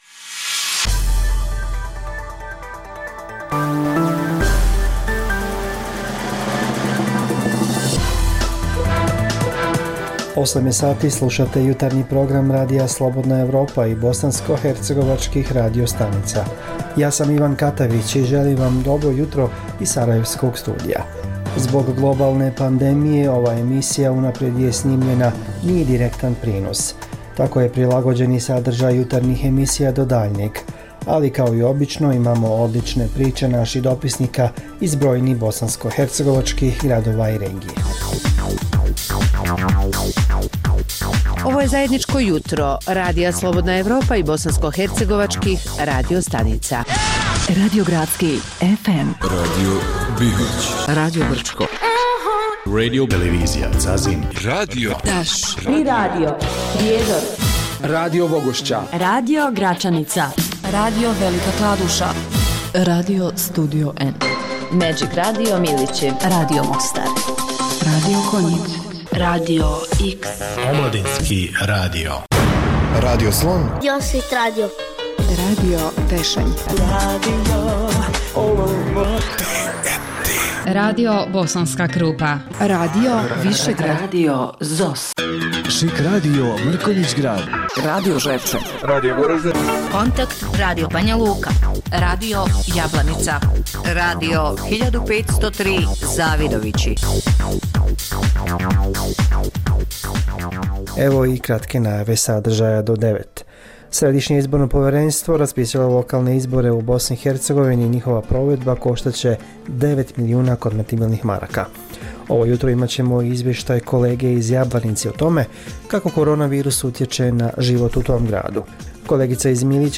Zbog pooštrenih mjera kretanja u cilju sprječavanja zaraze korona virusom, ovaj program je unaprijed snimljen. Poslušajte neke od priča iz raznih krajeva Bosne i Hercegovine.